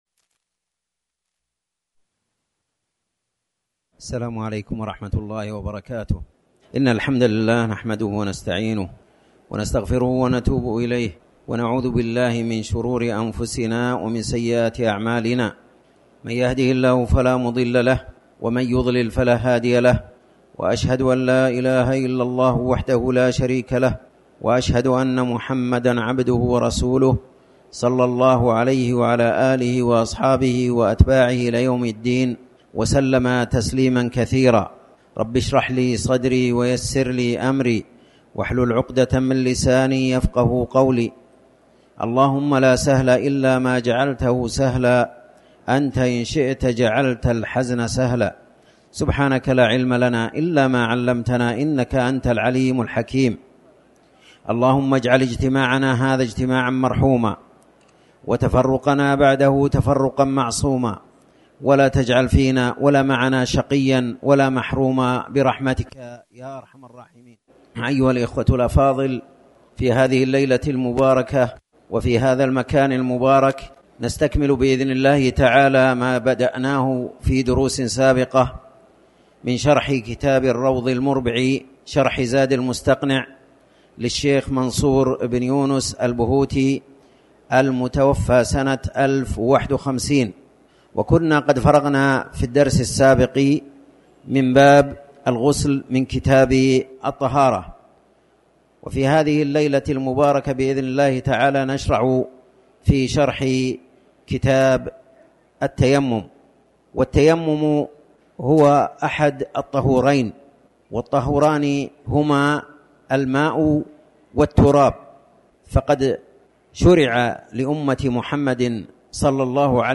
تاريخ النشر ١٧ ربيع الثاني ١٤٤٠ هـ المكان: المسجد الحرام الشيخ